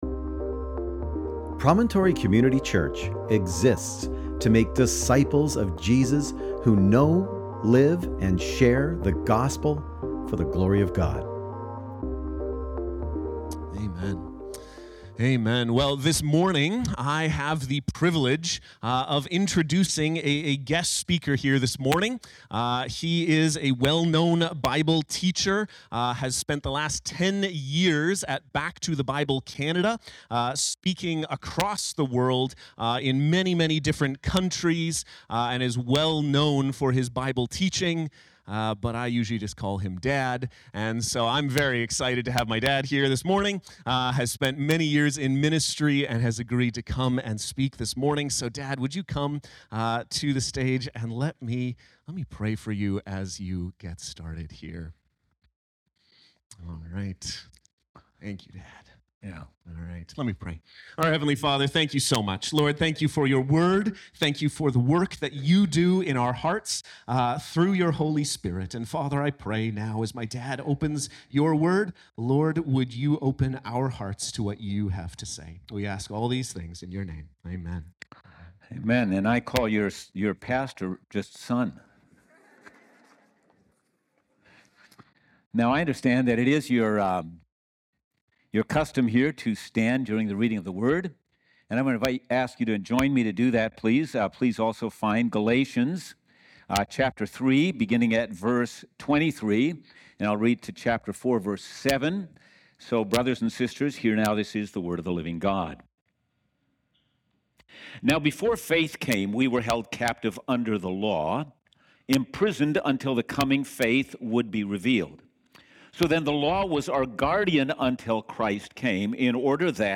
Sermon Text: Galatians 3:23-4:7